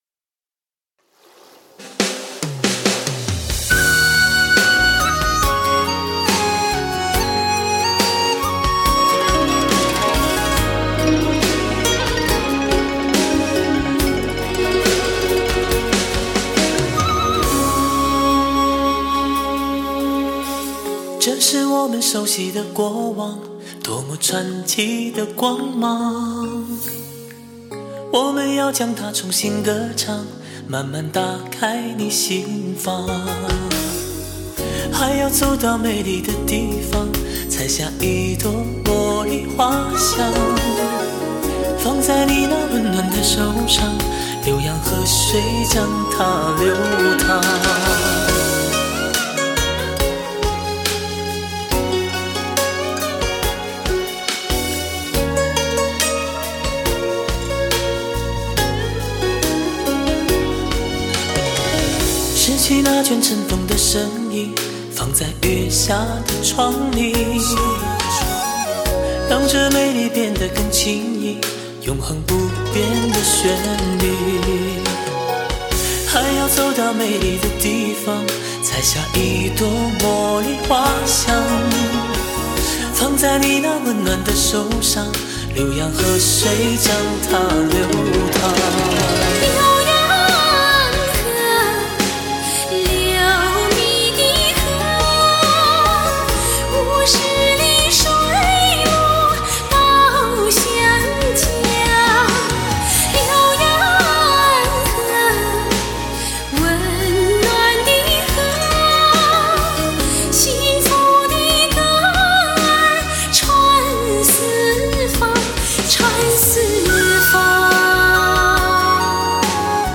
自由行走于各种音乐风格之间
著名器乐演唱组合，他们将新颖独特的双排键表演与各种风格的跨界演唱完美融合，开创了舞台上的全新表演模式。